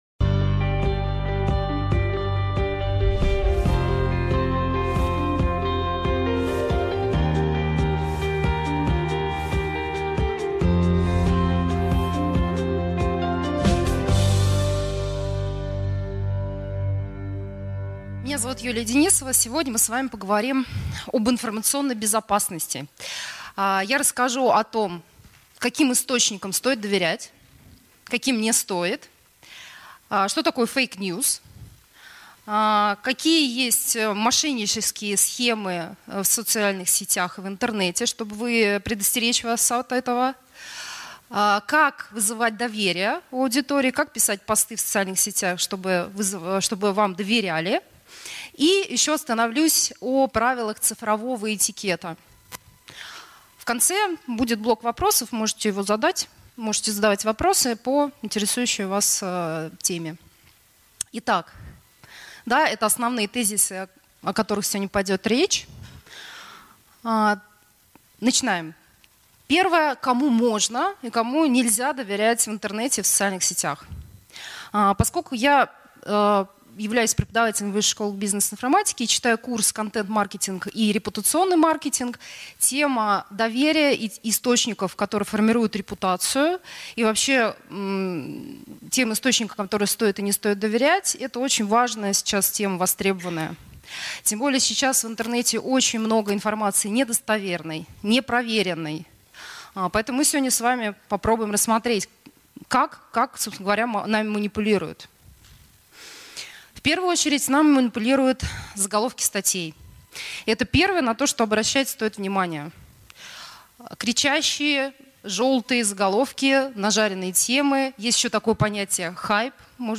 Аудиокнига Информационная безопасность: правила цифровой гигиены в интернете и соцсетя | Библиотека аудиокниг